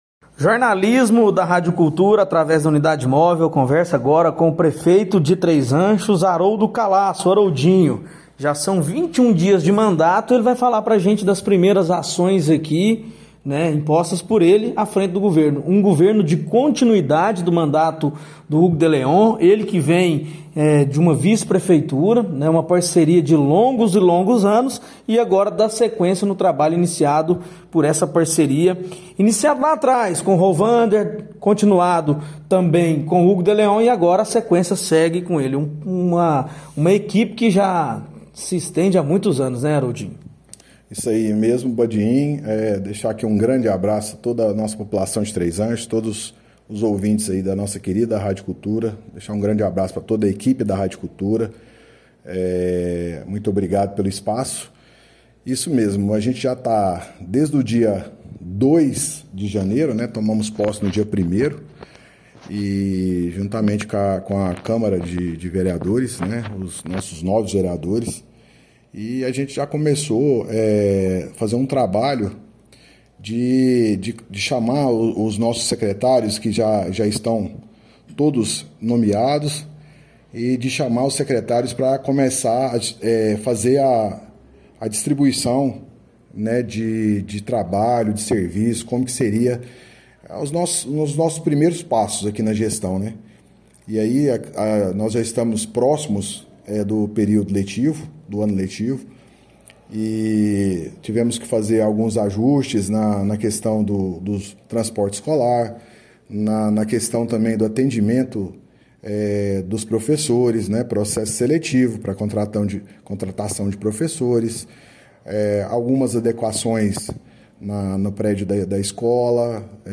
O Blog do Badiinho conversou com o prefeito Haroldo Calaça, o Haroldinho, sobre os 21 dias iniciais de sua administração em Três Ranchos.